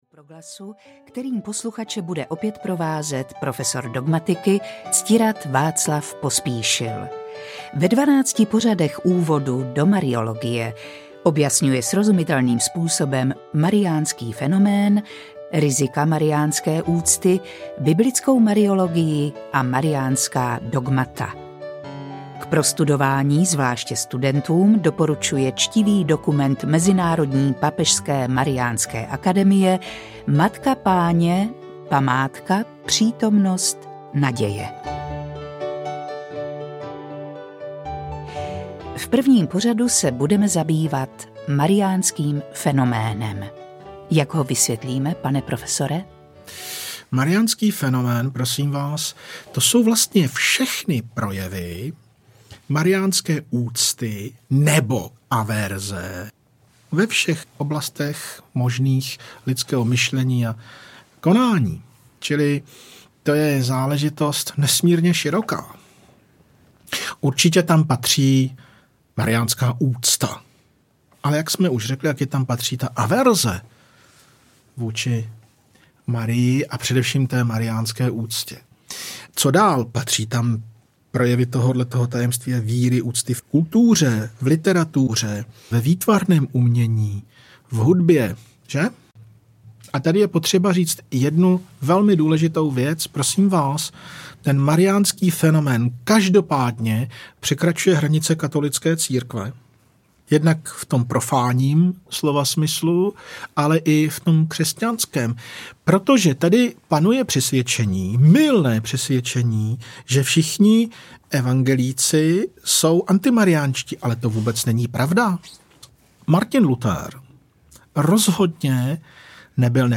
Úvod do mariologie: Matka Páně podle Písem audiokniha